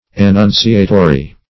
Annunciatory \An*nun"ci*a*to*ry\, a.